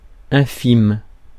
Ääntäminen
IPA: /ɛ̃.fim/